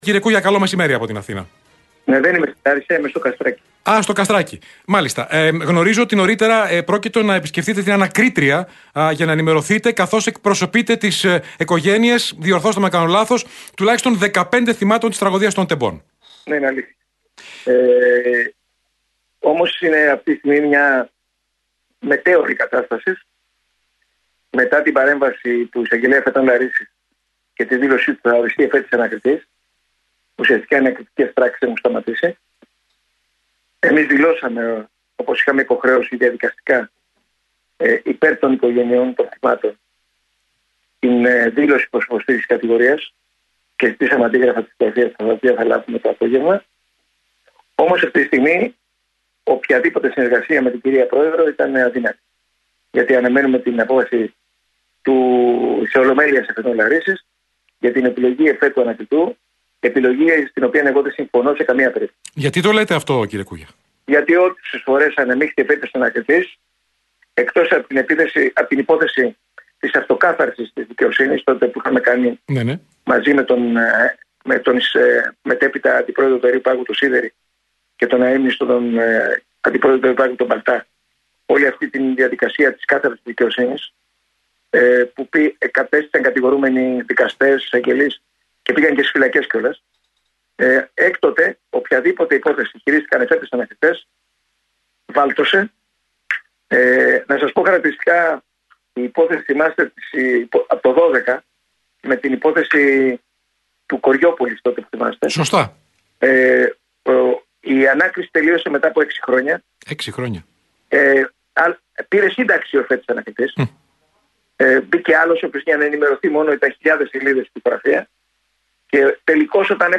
Την δυσαρέσκειά του για τον χρόνο που απαιτείται μέχρι να αρχίσει η ανάκριση για την τραγωδία στα Τέμπη, εξέφρασε μιλώντας στο κεντρικό μαγκαζίνο του Realfm